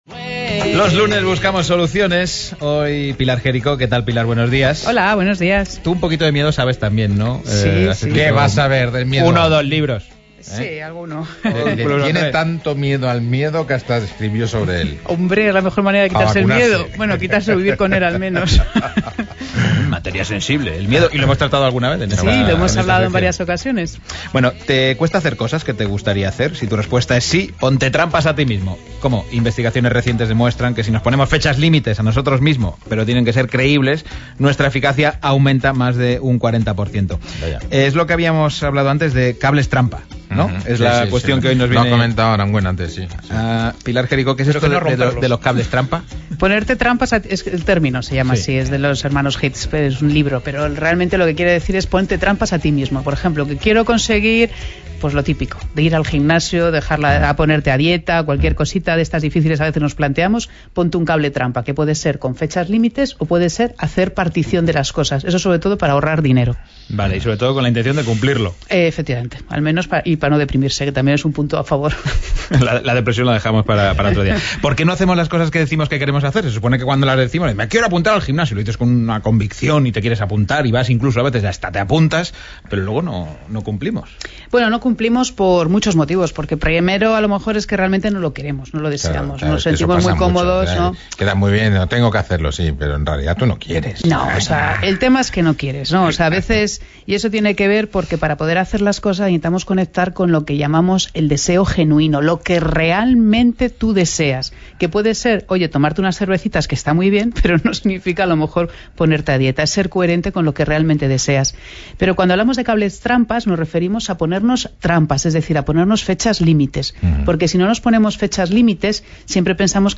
¿Te cuesta hacer cosas que te gustaría hacer? Si la respuesta es sí, ponte cables trampa a ti mismo. El lunes pasado expliqué qué son y cómo hacerlo en Las mañanas de RNE